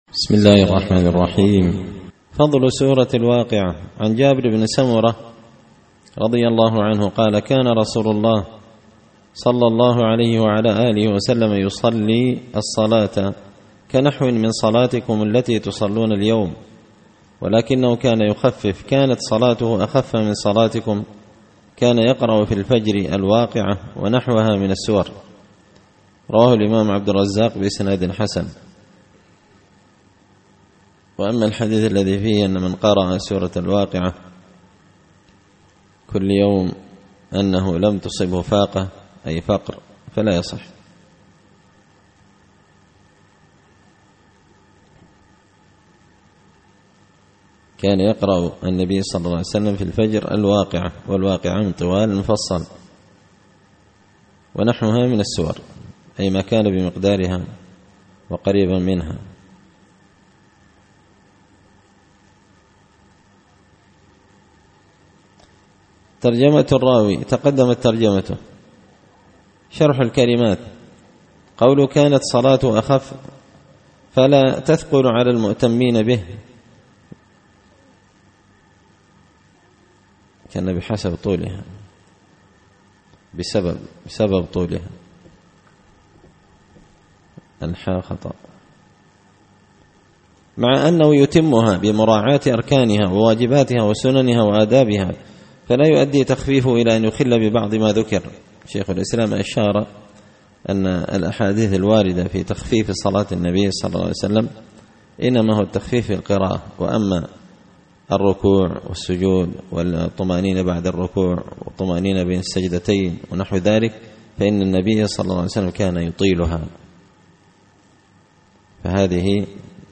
الأحاديث الحسان فيما صح من فضائل سور القرآن ـ الدرس التاسع والثلاثون
دار الحديث بمسجد الفرقان ـ قشن ـ المهرة ـ اليمن